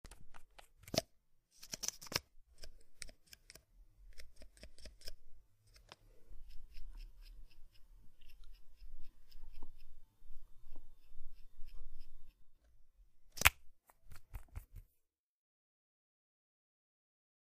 Unscrew Mascara Tube And Put Brush In / Out, Apply